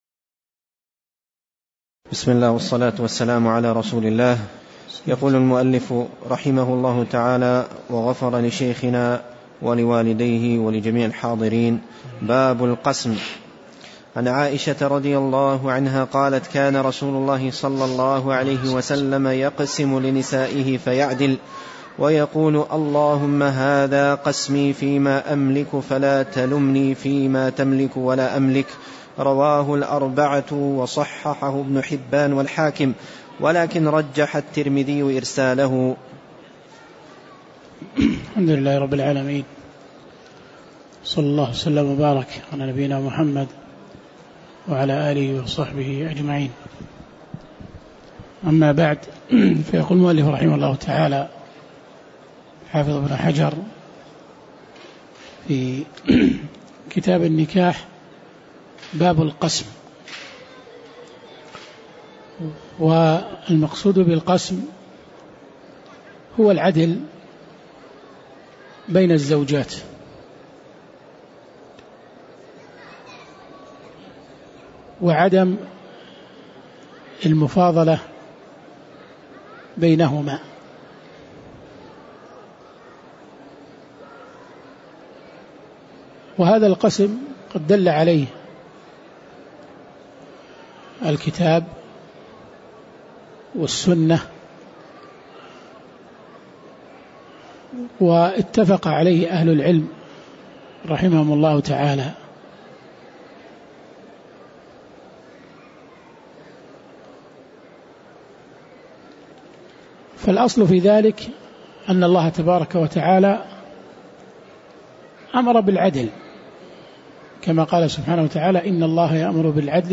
تاريخ النشر ٢٩ صفر ١٤٣٨ هـ المكان: المسجد النبوي الشيخ